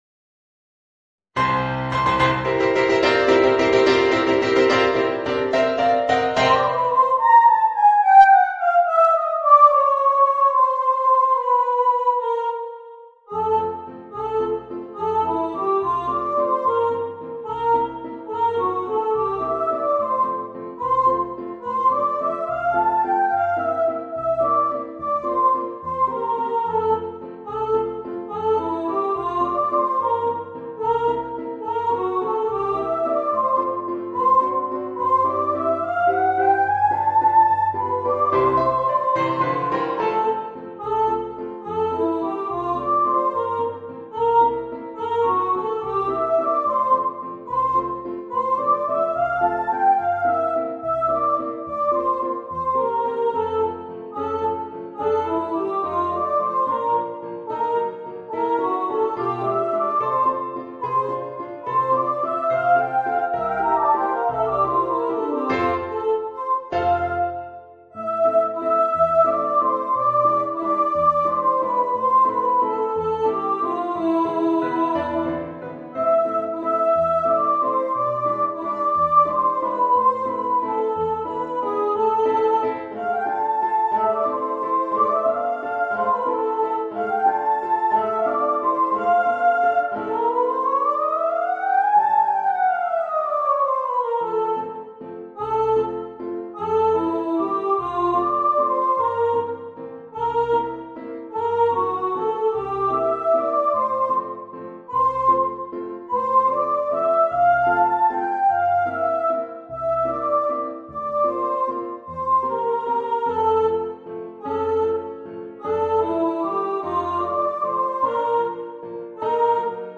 Voicing: Voice and Piano